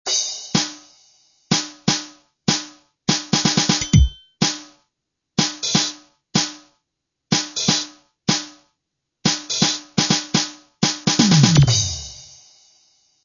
stopy_aids_doplnkove_bicie_eff.mp3